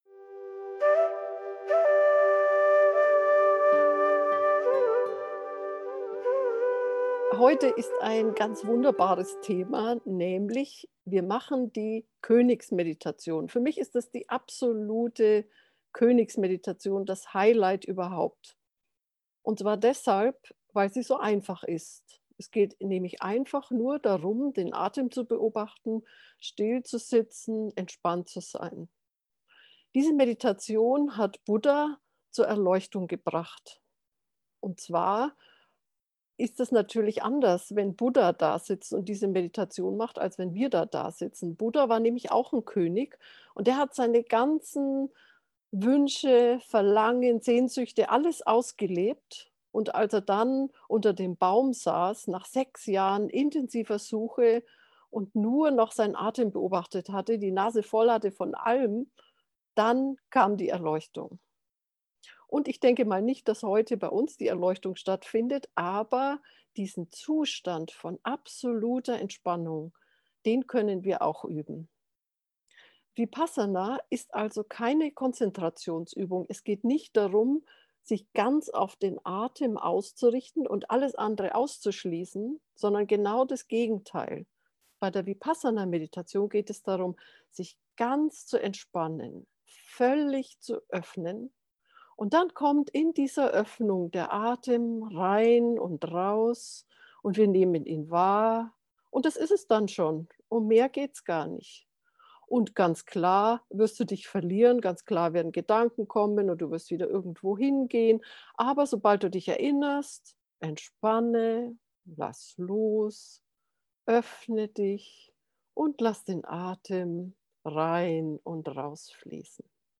vipassana-atemmeditation-gefuehrte-meditation